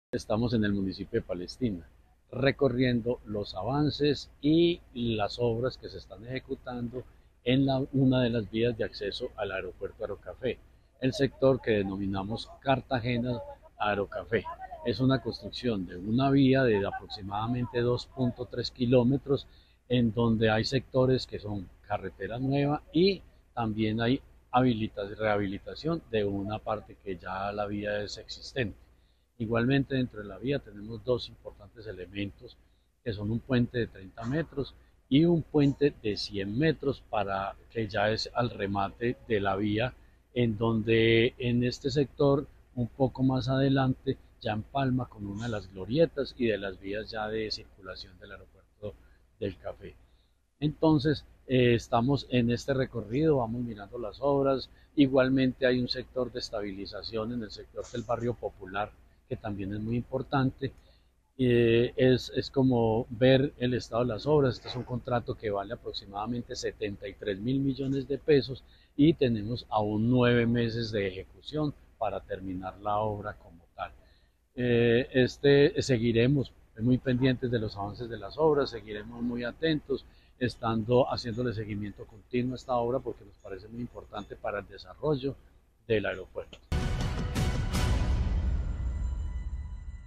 Jorge Ricardo Gutiérrez Cardona, Secretario de Infraestructura de Caldas.